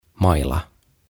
Tuhat sanaa suomeksi - Ääntämisohjeet - Sivu 4